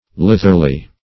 Litherly \Li"ther*ly\, a.